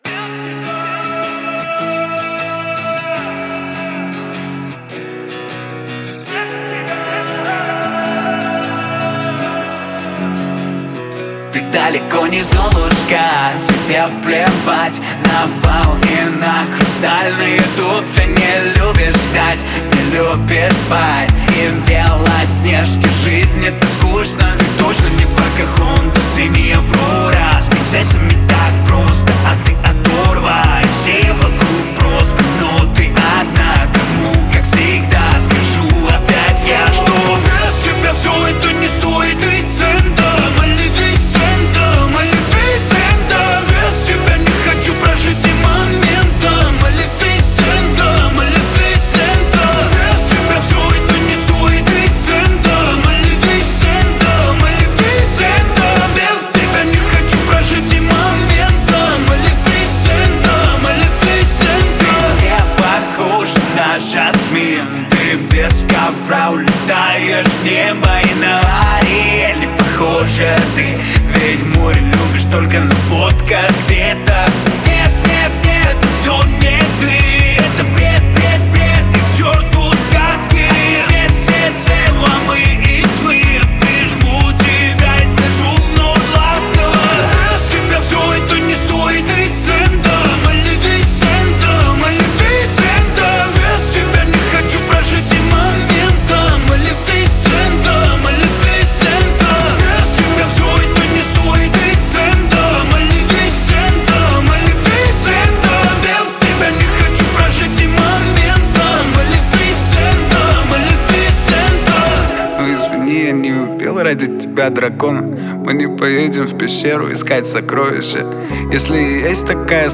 Русские песни